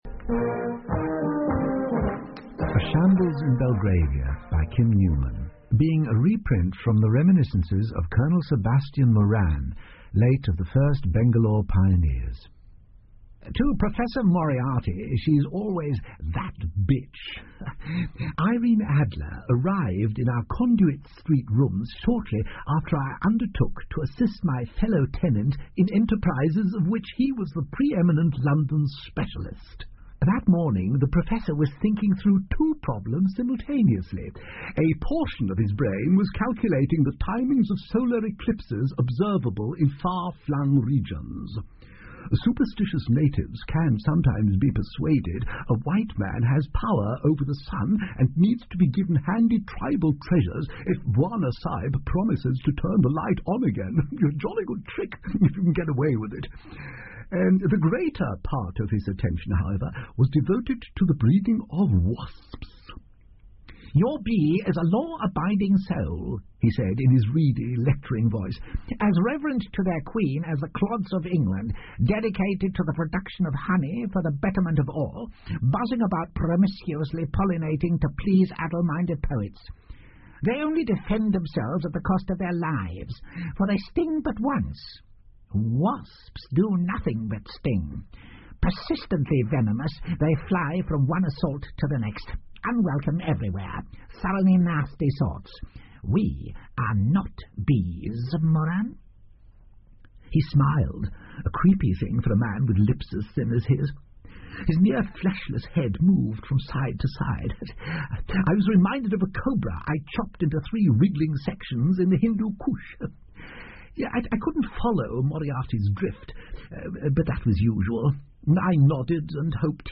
在线英语听力室福尔摩斯广播剧 Cult-A Shambles In Belgravia 1的听力文件下载,英语有声读物,英文广播剧-在线英语听力室